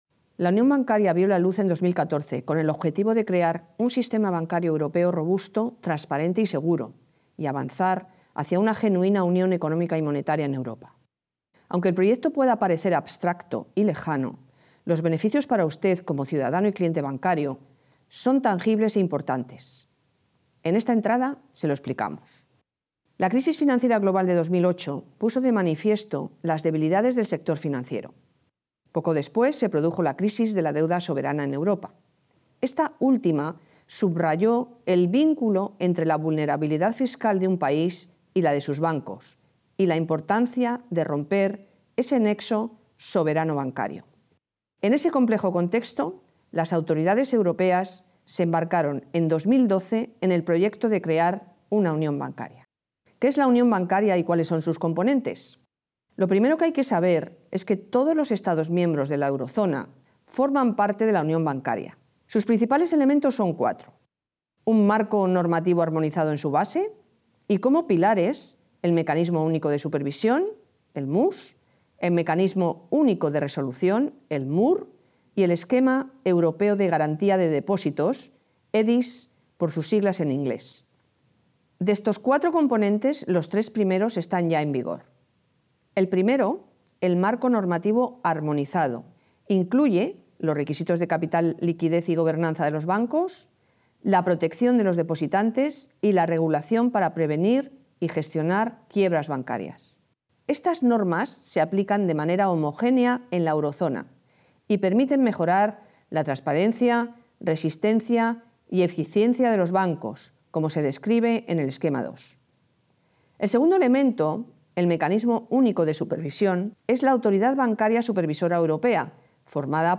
BdE_Locucion_Subgobernadora.mp3